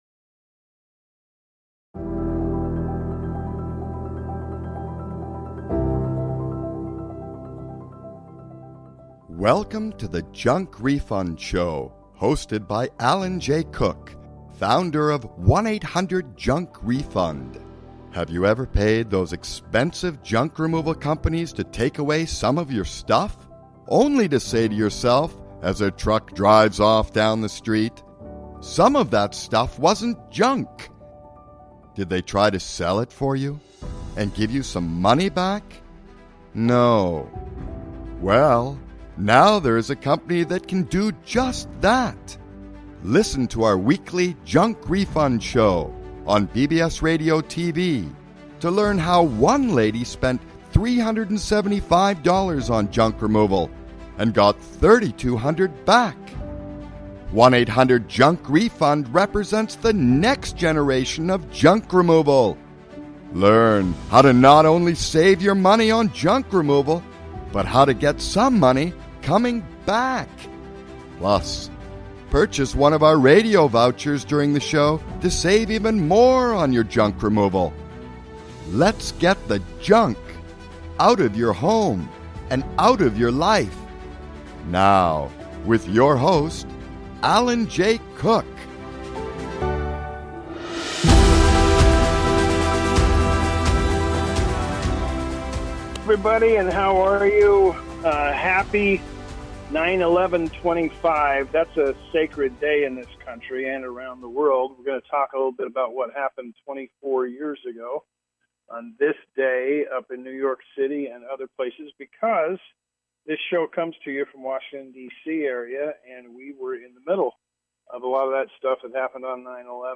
Talk Show Episode, Audio Podcast, Junk Refund Show and From the Washington DC Area, Remembering 911, Flags flying at half-mast, A Historic Day.